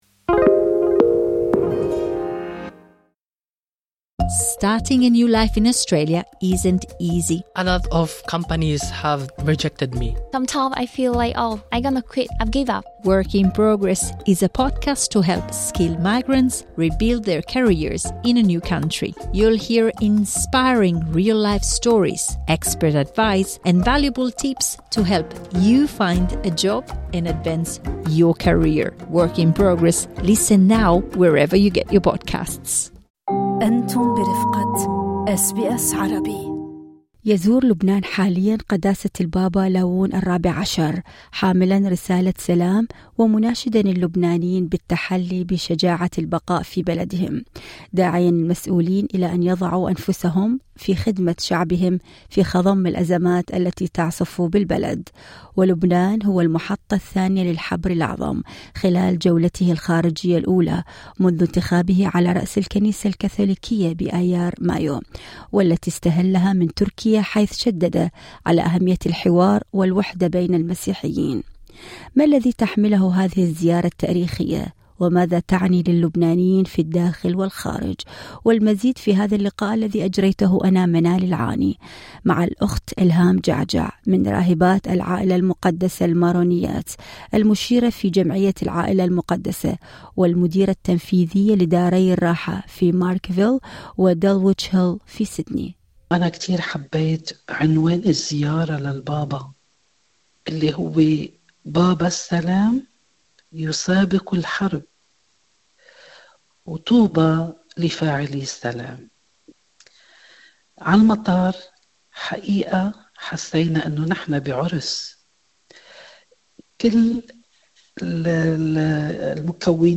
التفاصيل في اللقاء الصوتي اعلاه